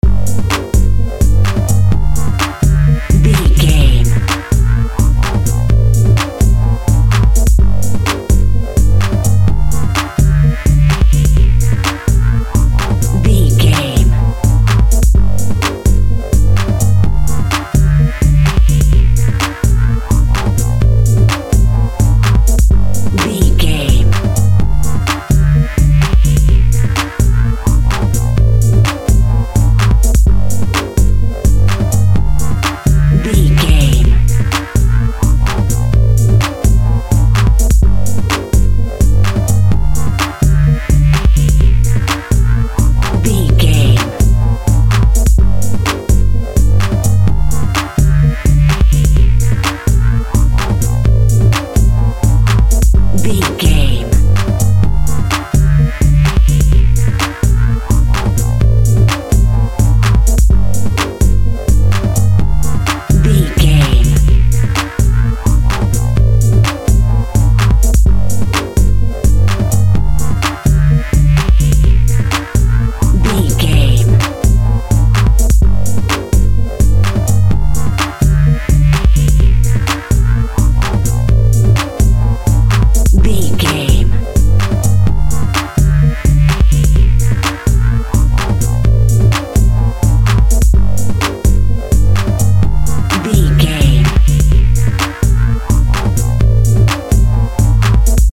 Aeolian/Minor
dark
energetic
driving
futuristic
suspense
drum machine
synthesiser
Drum and bass
break beat
sub bass
synth lead
synth bass